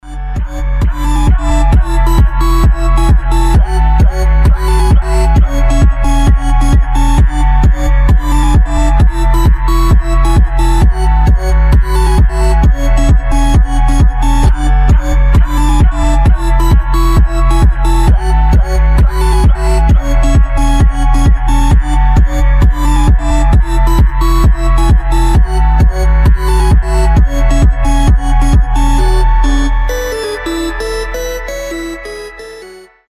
• Качество: 320, Stereo
громкие
энергичные
звонкие
электронные
Электронный гиперпоп (hyperpop) и тиктоккор (tiktokcore)